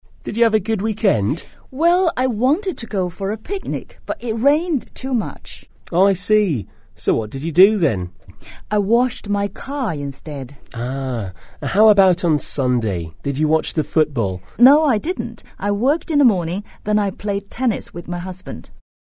英语初学者口语对话第19集：你的周末过得好吗？